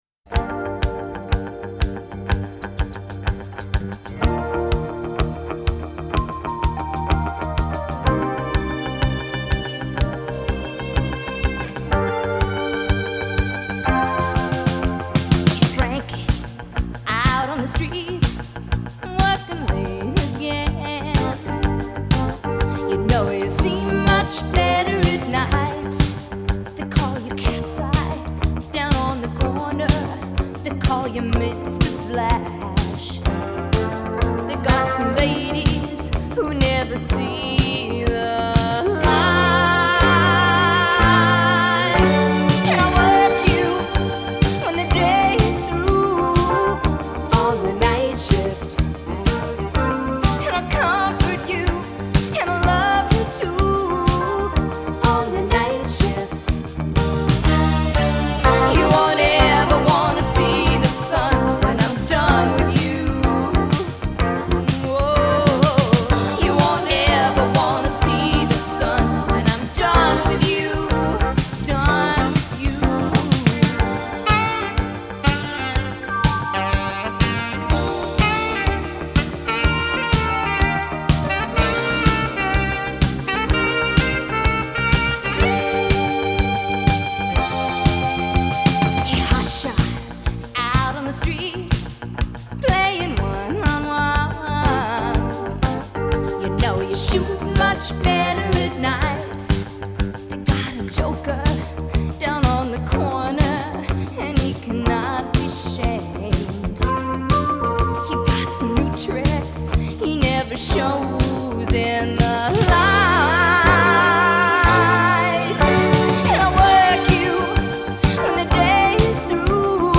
so please enjoy the vinyl rip that I have included below.